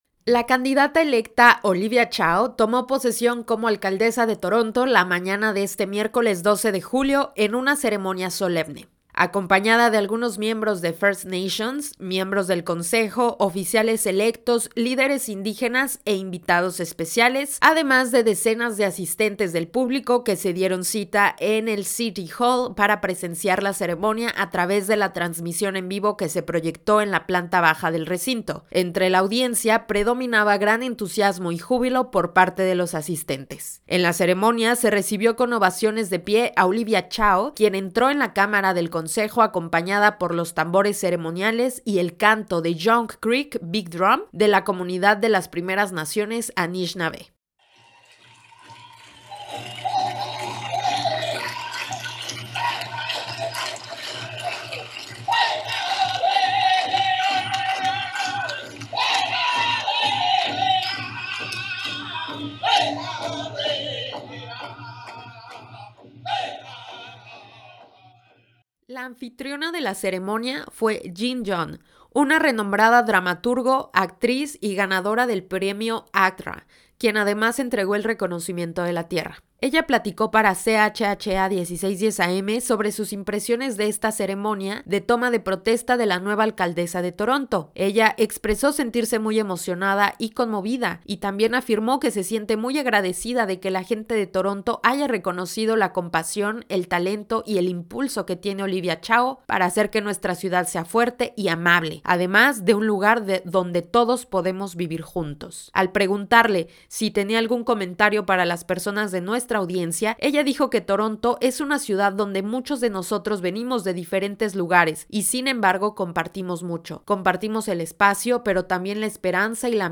La anfitriona de la ceremonia, fue Jean Yoon, una renombrada dramaturga, actriz y ganadora del premio ACTRA quien en conversación para CHHA 1610 AM platicó sobre sus impresiones de la ceremonia de toma de protesta de la nueva alcaldesa de Toronto, y expresó sentirse muy emocionada y conmovida, además afirmó sentirse muy agradecida de que la gente de Toronto haya reconocido la compasión, el talento y el impulso que tiene Olivia Chow para hacer que la ciudad sea fuerte y amable además de un lugar donde todos podamos vivir juntos.